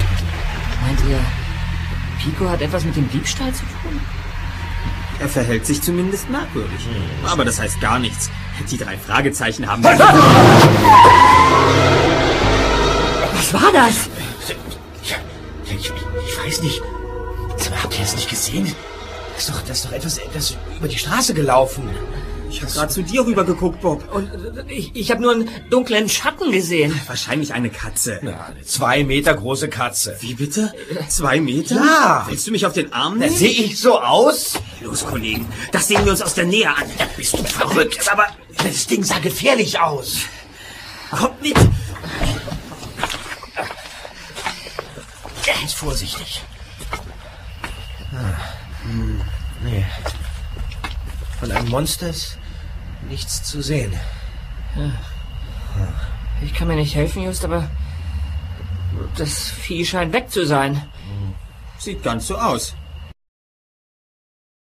- Das schwarze Monster | Physical CD Audio drama
Erzähler - Matthias Fuchs
Justus Jonas, Erster Detektiv - Oliver Rohrbeck
Peter Shaw, Zweiter Detektiv - Jens Wawrczeck
Bob Andrews, Recherchen und Archiv - Andreas Fröhlich